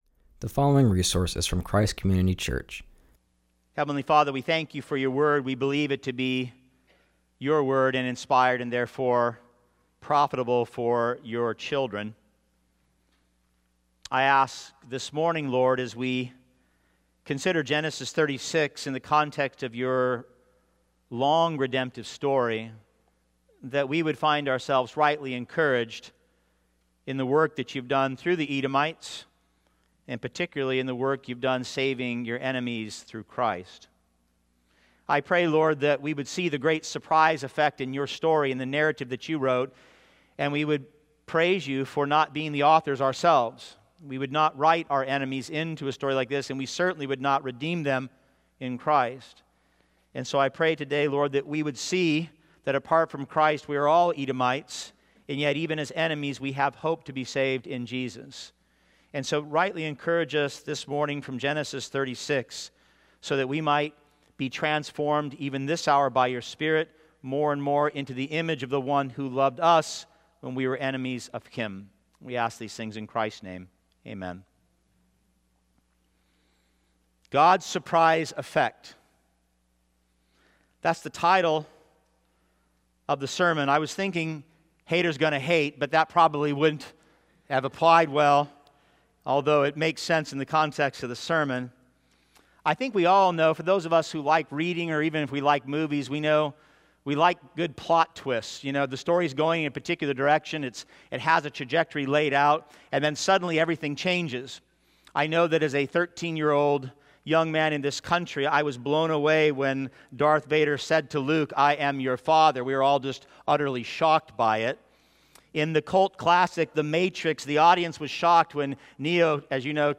preaches from Genesis 36:1-43.